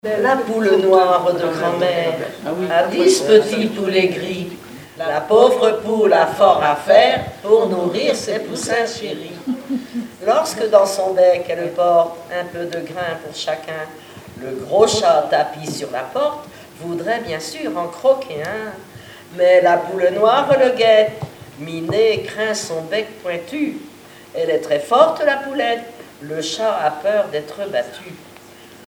formulette enfantine : amusette
Chansons, formulettes enfantines
Pièce musicale inédite